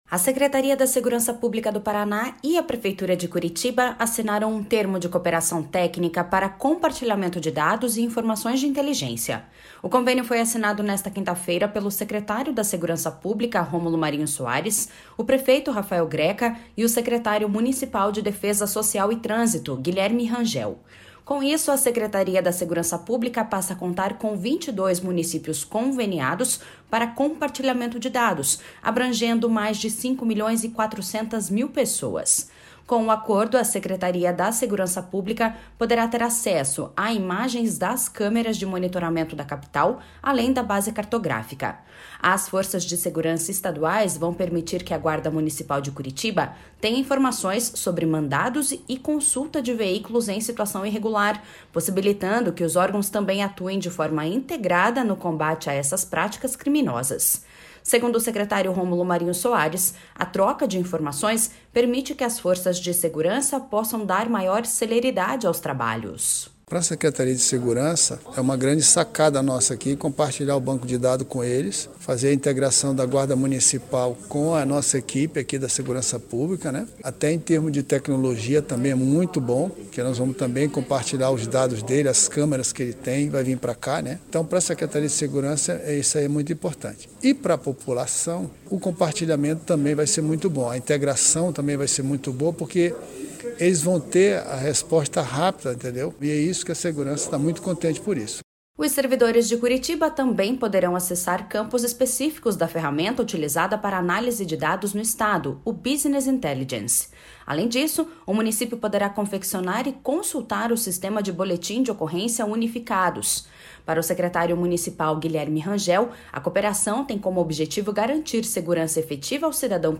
Segundo o secretário Romulo Marinho Soares, a troca de informações permite que as forças de segurança possam dar maior celeridade aos trabalhos.// SONORA ROMULO MARINHO SOARES.//
Para o secretário municipal Guilherme Rangel, a cooperação tem como objetivo garantir segurança efetiva ao cidadão curitibano e integração entre instituições.// SONORA GUILHERME RANGEL.//